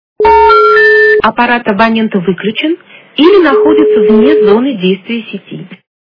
При прослушивании Женский голос - Аппарат абонента выключен или находится вне зоны сети качество понижено и присутствуют гудки.
Звук Женский голос - Аппарат абонента выключен или находится вне зоны сети